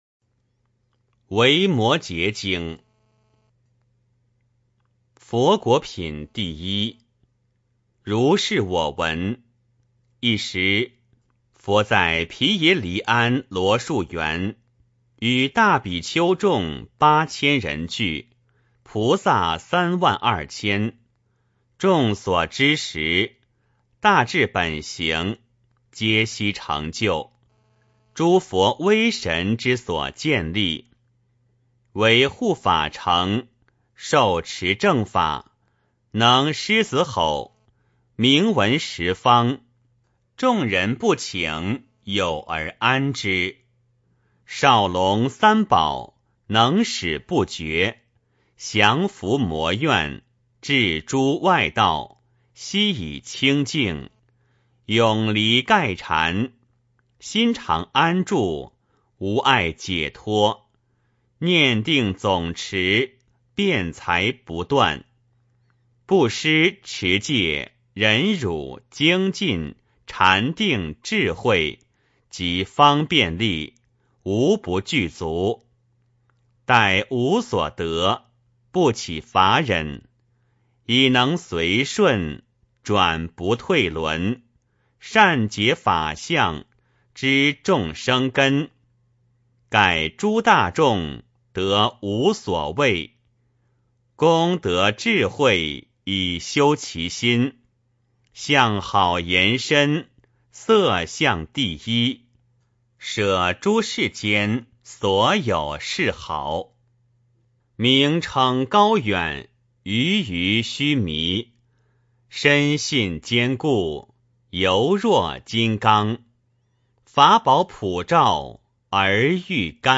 维摩诘经-01-念诵 - 诵经 - 云佛论坛